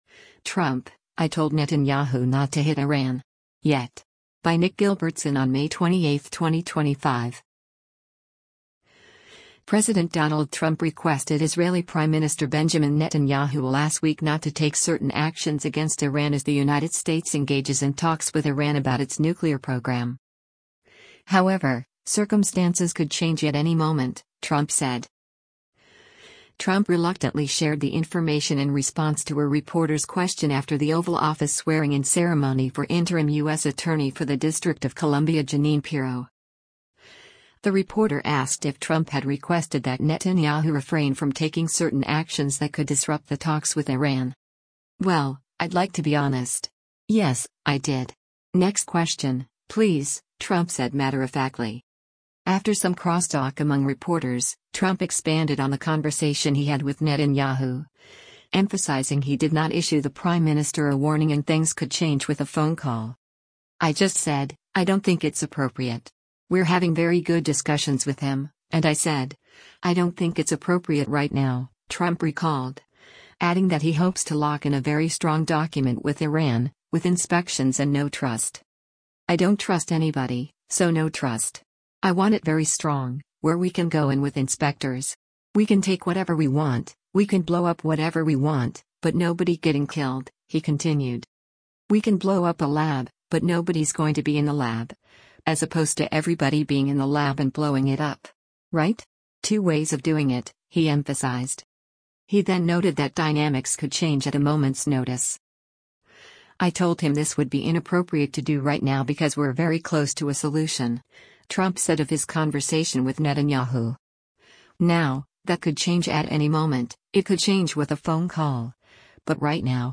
Trump reluctantly shared the information in response to a reporter’s question after the Oval Office swearing-in ceremony for interim U.S. Attorney for the District of Columbia Jeannine Pirro.
“Well, I’d like to be honest. Yes, I did. Next question, please,” Trump said matter-of-factly.
After some cross-talk among reporters, Trump expanded on the conversation he had with Netanyahu, emphasizing he did not issue the prime minister “a warning” and things could change with a phone call.